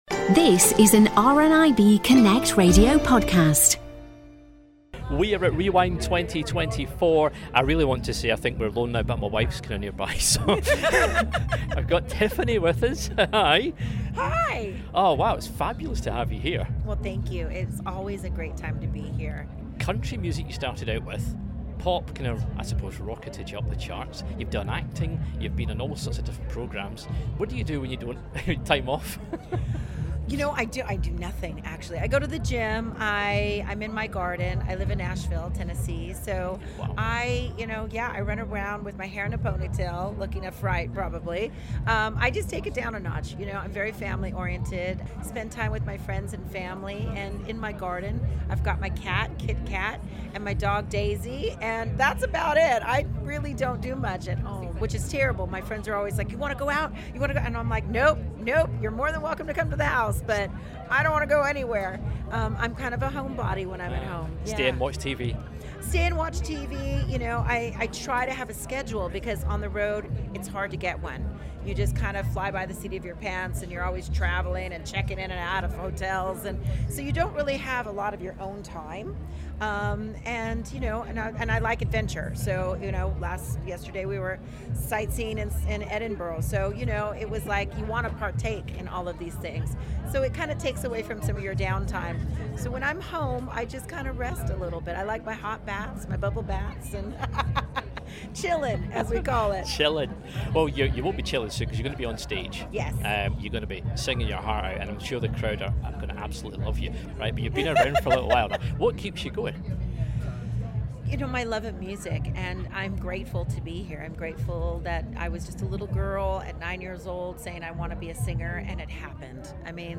Rewind Scotland 2024 returned to the spectacular grounds of Scone Palace in Perth on Friday 19th till Sunday 21st of July to celebrate music icons of the 80s and beyond.